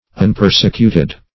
unpersecuted - definition of unpersecuted - synonyms, pronunciation, spelling from Free Dictionary
unpersecuted.mp3